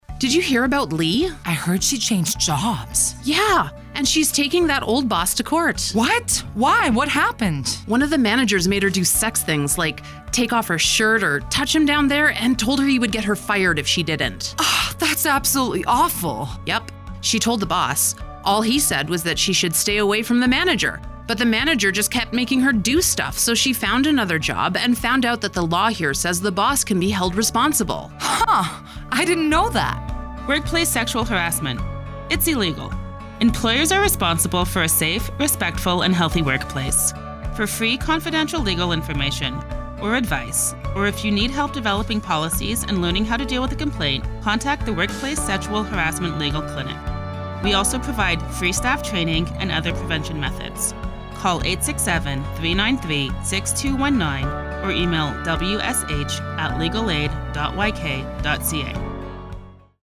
Workplace Sexual Harassment Radio Ads
"Employer Liability" Radio Ad